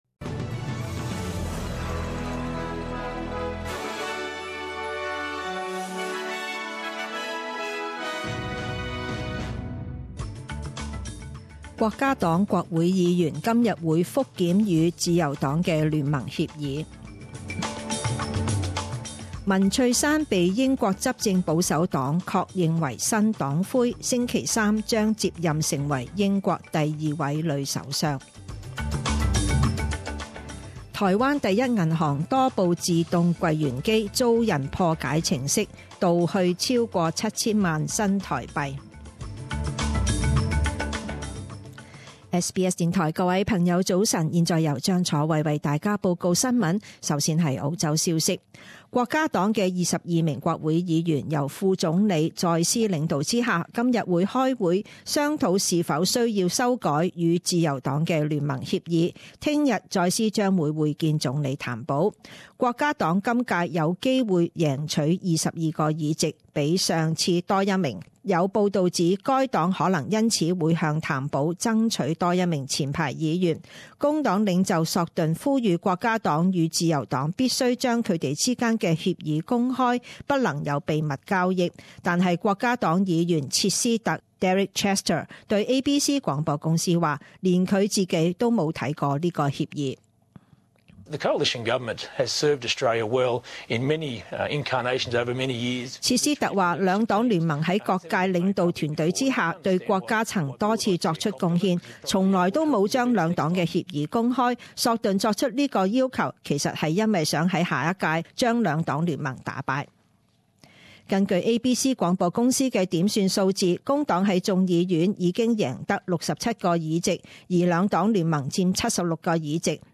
七月十二日十点钟新闻报导